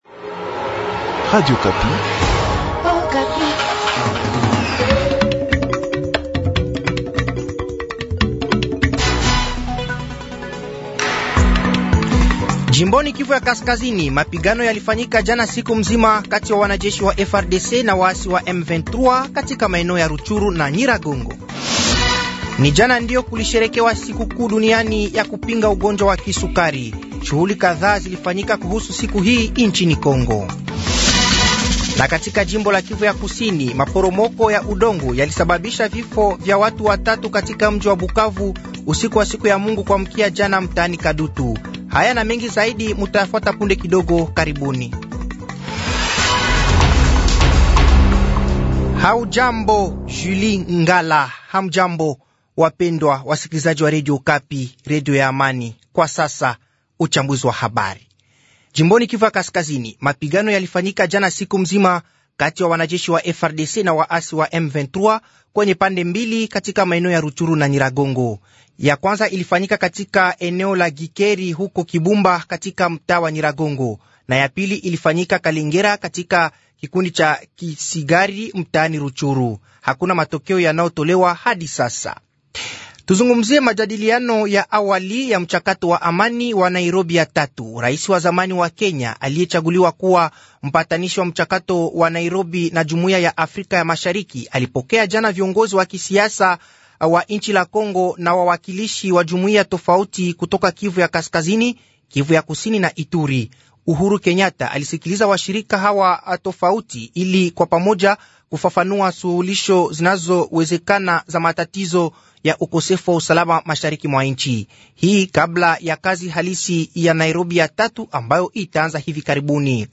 Journal matin
Katika mahojiano na Radio Okapi kutoka Luanda nchini Angola aliko, Christoph Lutundula anafasiria tena matamshi yake yaliyotafsiriwa vibaya sikun ya tano iliyopita kuhusu kuzuiwa kwa inchi kupata silaha.